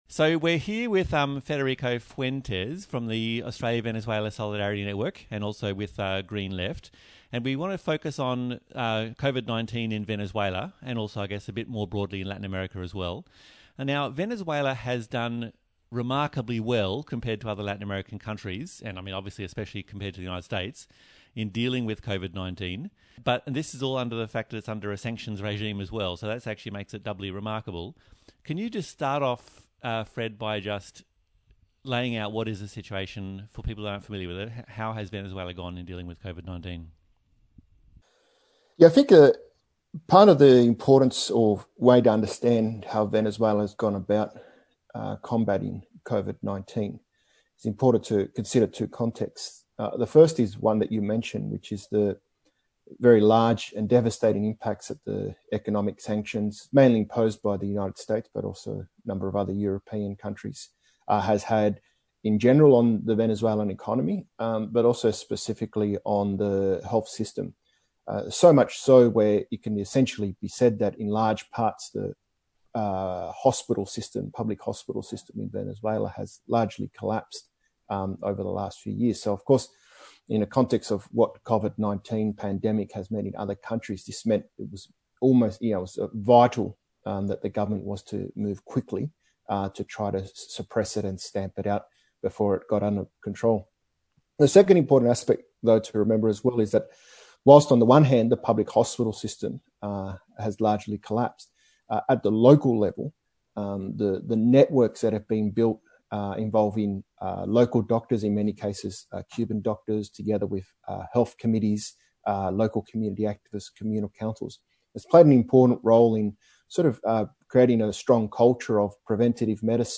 Interviews and Discussions